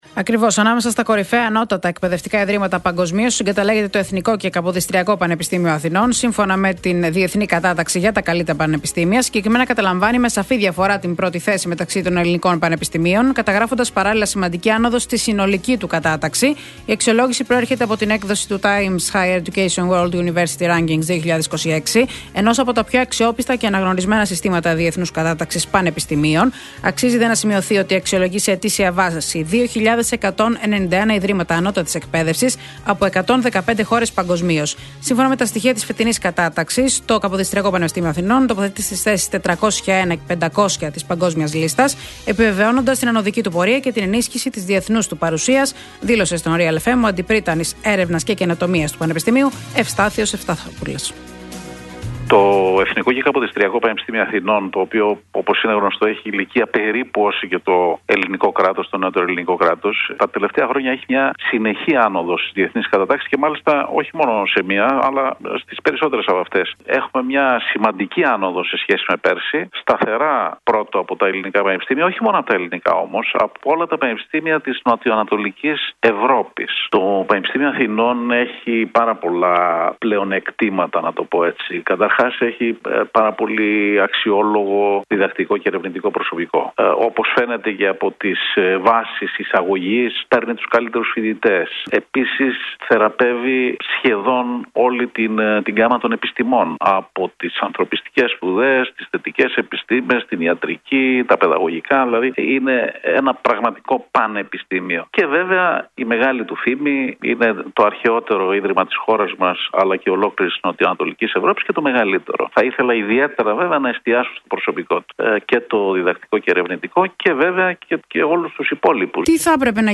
Για την εν λόγω διάκριση μίλησε στον Real FM